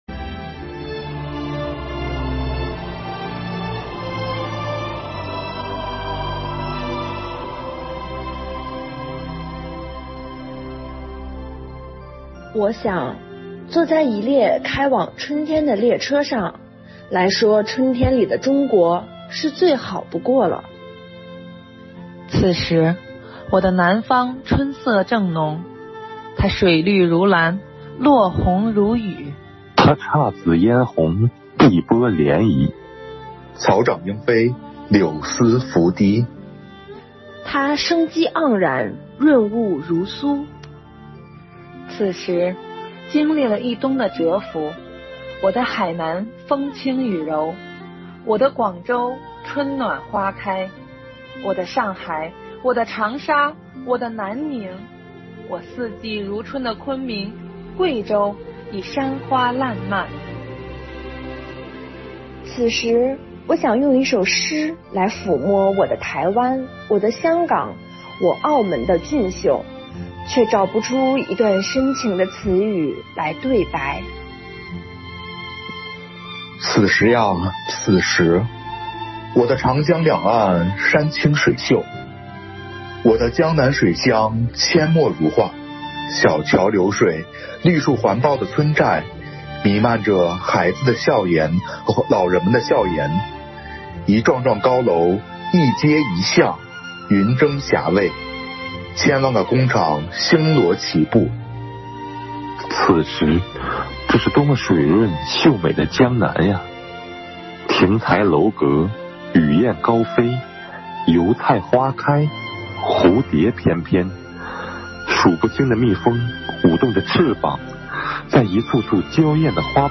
“四月，我们和春天在一起”主题云朗诵会
合诵《春天里的中国》
生活好课堂幸福志愿者中国钢研朗读服务（支）队
《春天里的中国》合诵：中国钢研朗读支队.mp3